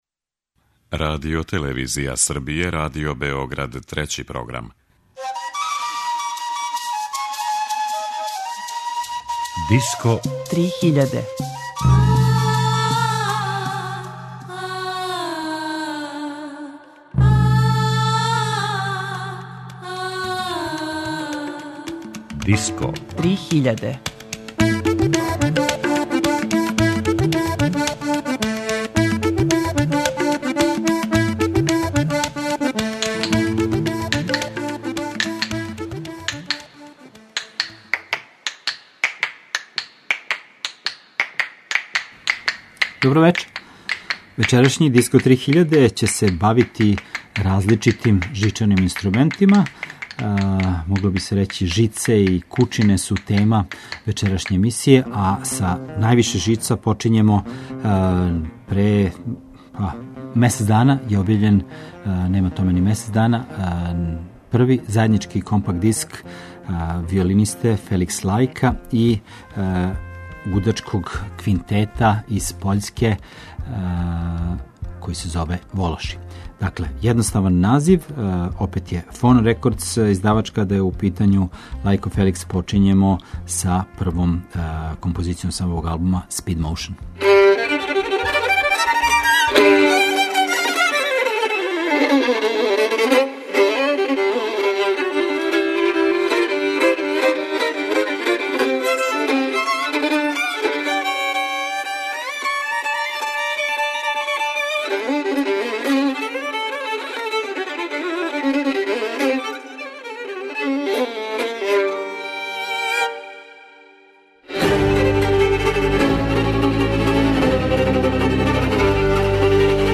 Дрво и жице
world music